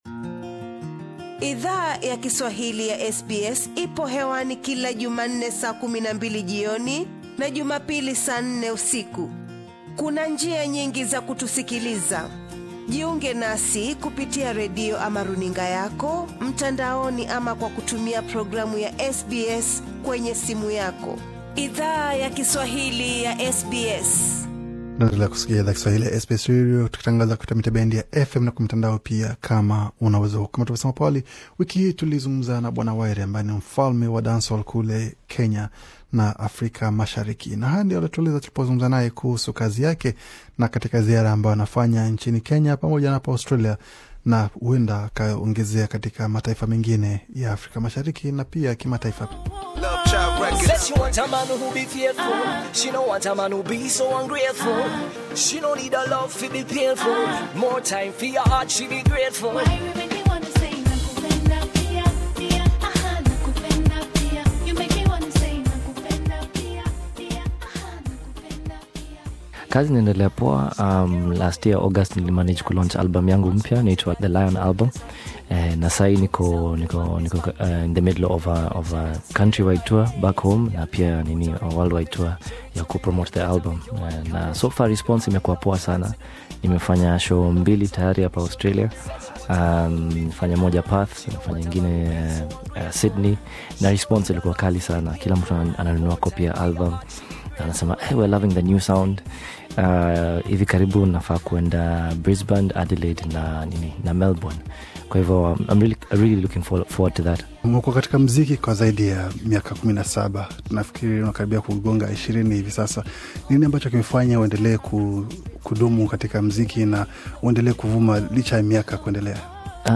East Africa's Dancehall King Wyre speaks to SBS Swahili